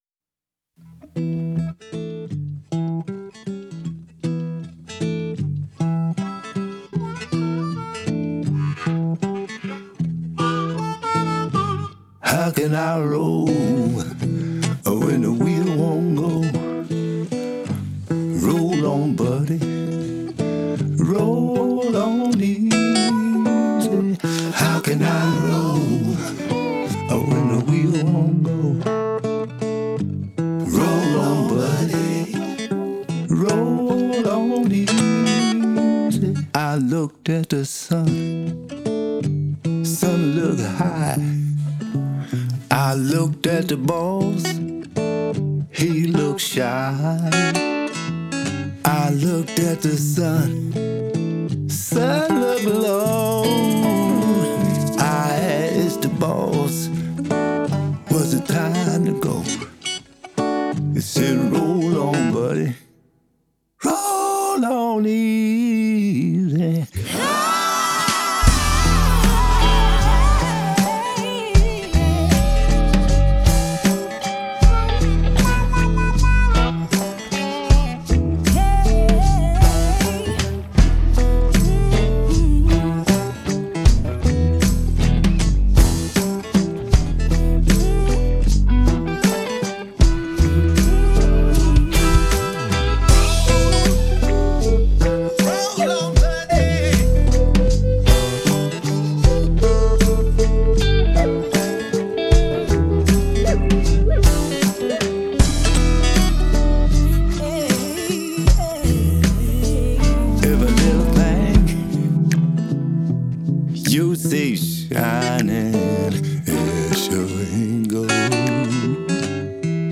blues troubadour